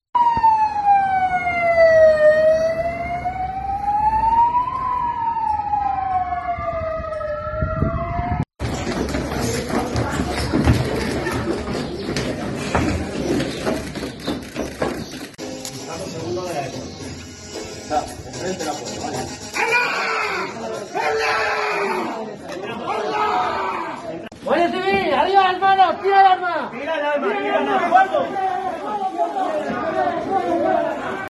Sonido ambiente del simulacro terrorista en Zamora